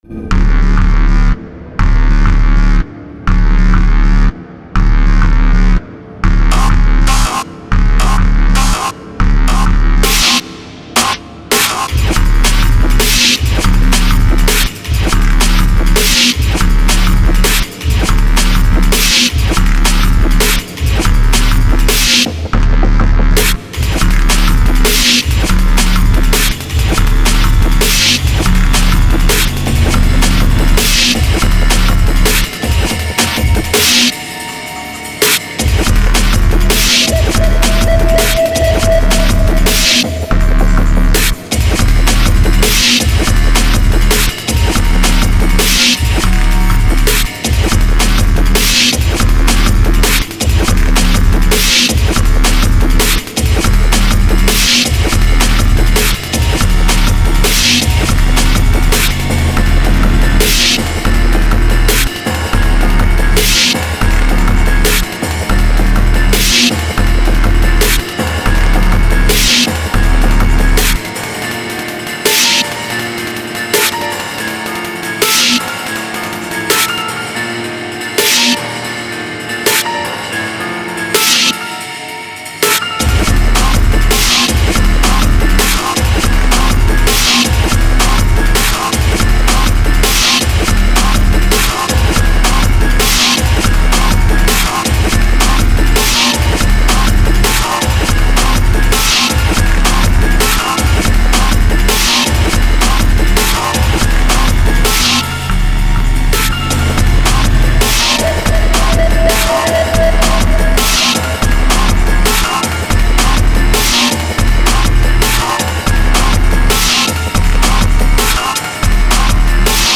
HALFSTEP